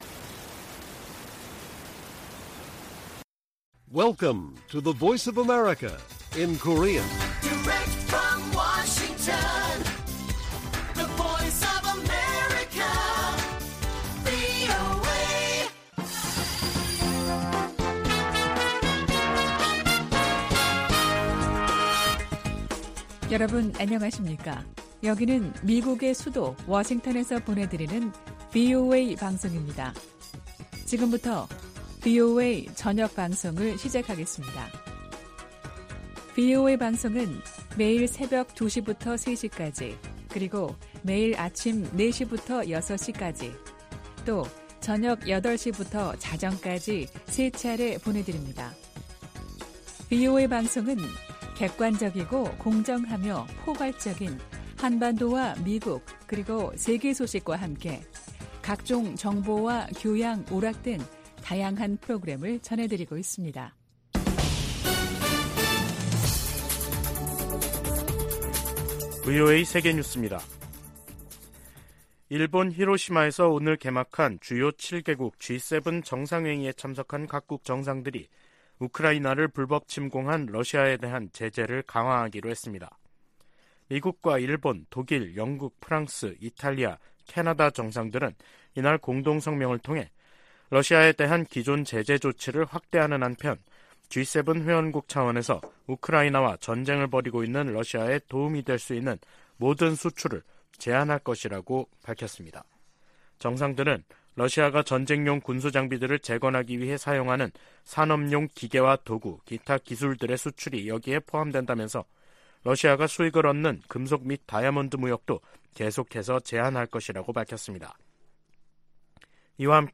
VOA 한국어 간판 뉴스 프로그램 '뉴스 투데이', 2023년 5월 19일 1부 방송입니다. 미국과 일본 정상이 히로시마에서 회담하고 북한의 핵과 미사일 문제 등 국제 현안을 논의했습니다. 윤석열 한국 대통령이 19일 일본 히로시마에 도착해 주요7개국(G7) 정상회의 참가 일정을 시작했습니다. 북한이 동창리 서해발사장에 새로짓고 있는 발사대에서 고체연료 로켓을 시험발사할 가능성이 높다고 미국 전문가가 분석했습니다.